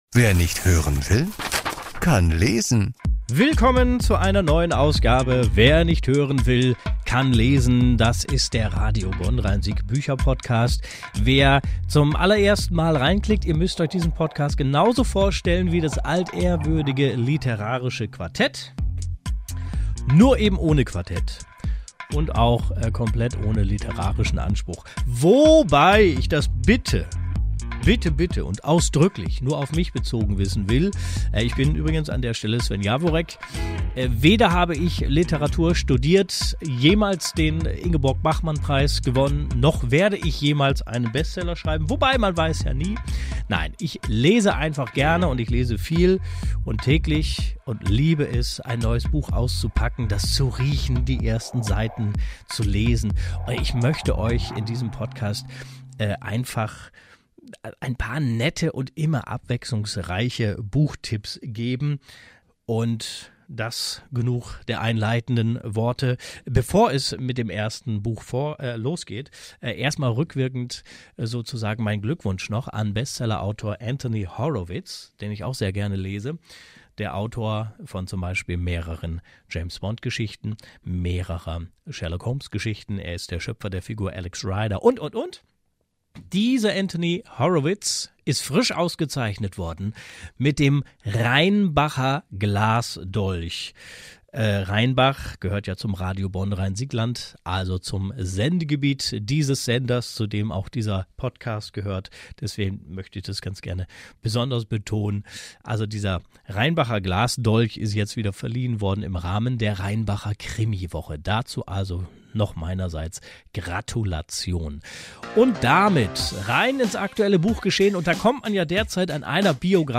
Aus der Reihe "Das Buch zur Serie" gibt es diesmal ein Interview mit Sebastian Fitzek über dessen Debütroman "Die Therapie" und die aktuelle Serienverfilmung des Buches.